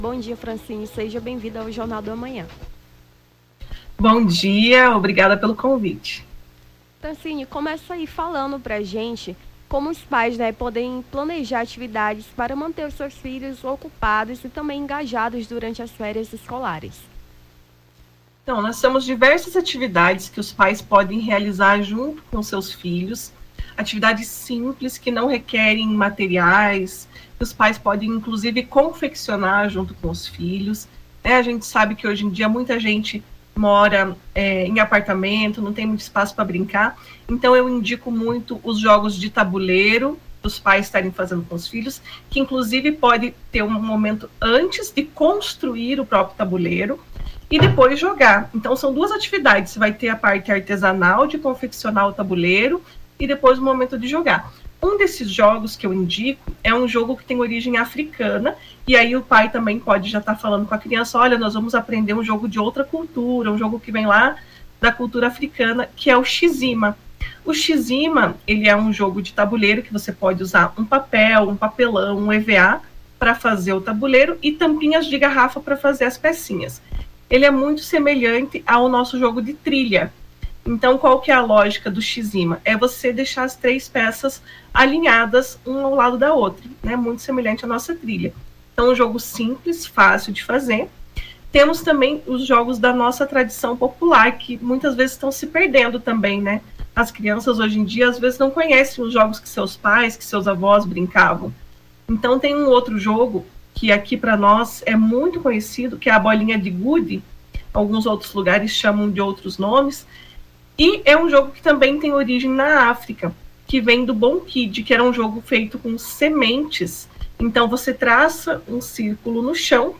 Rio Branco